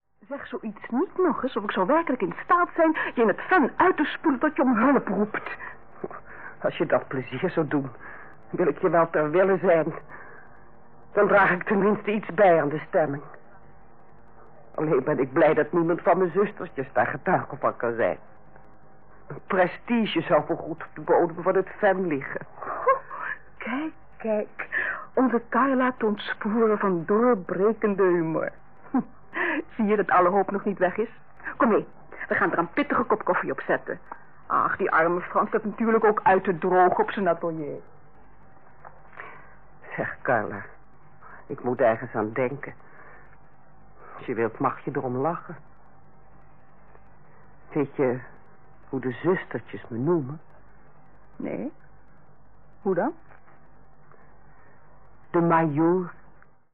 Hoorspel anders: Majoor Carla
Hoorspel-Anders-Majoor-Carla.mp3